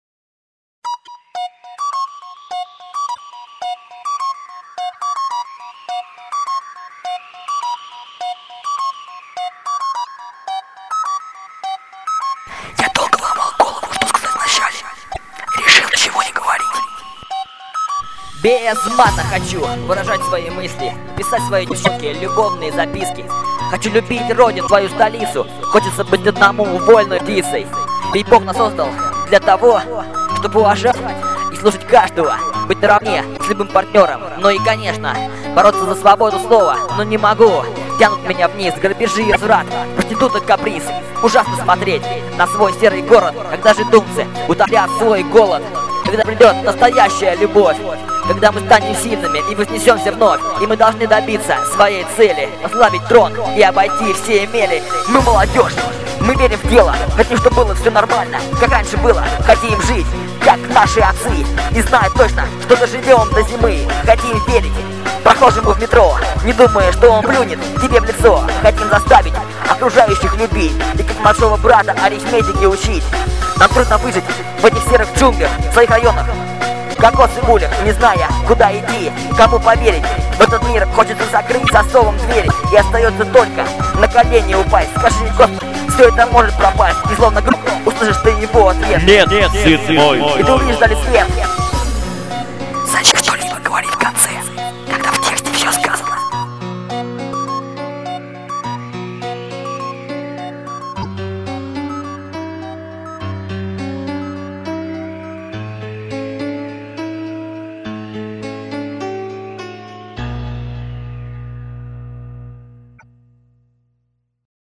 быстрая версия